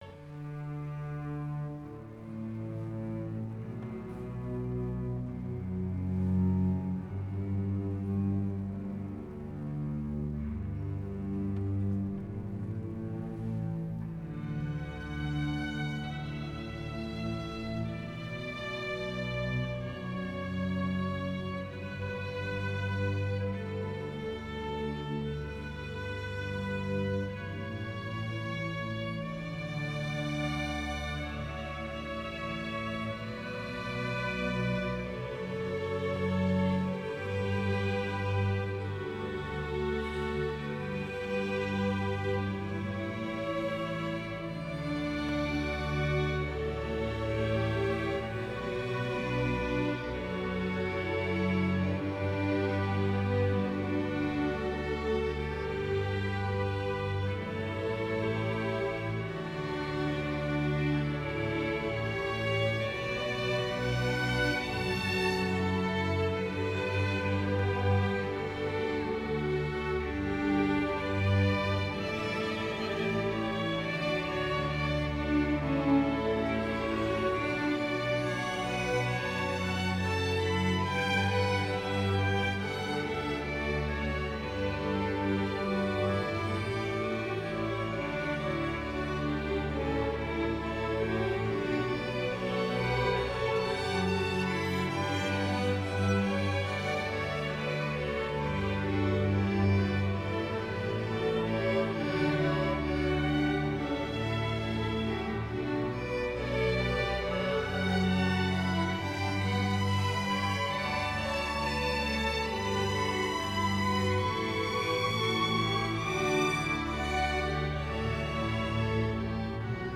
Quintencircel Dülmen
Johann Pachelbel - Kanon D-Dur für Streichorchester